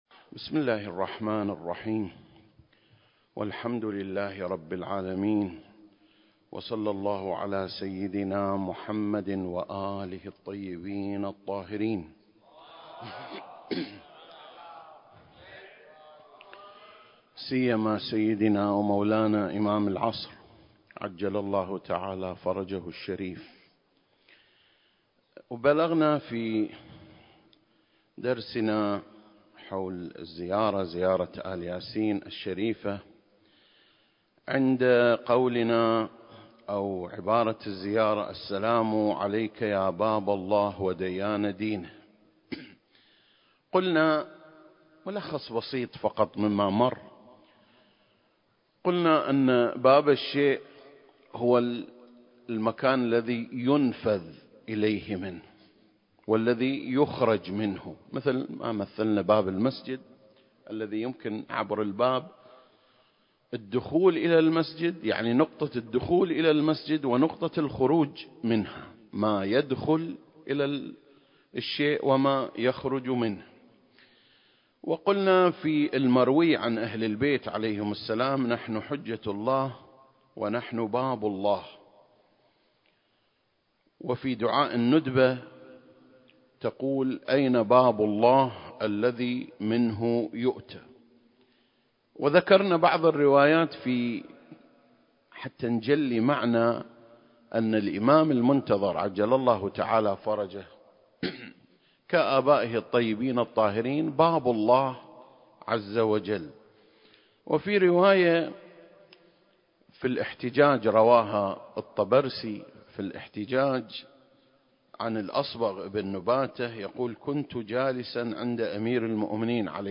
سلسلة: شرح زيارة آل ياسين (34) - باب الله (2) المكان: مسجد مقامس - الكويت التاريخ: 2021